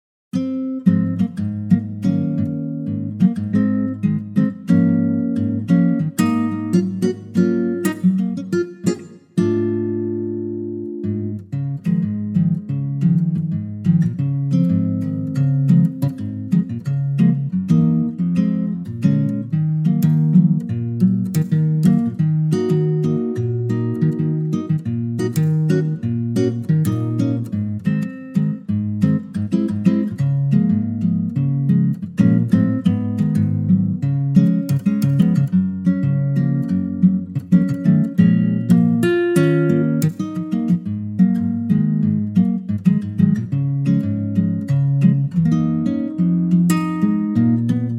key - Db - vocal range - F to Gb